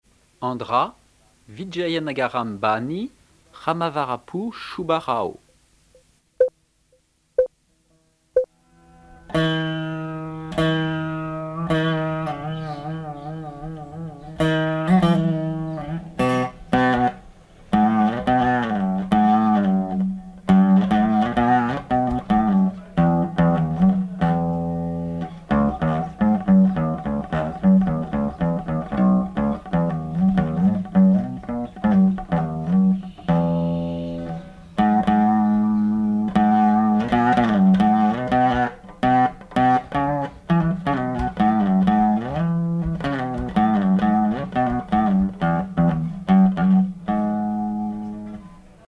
Musique carnatique
Pièce musicale inédite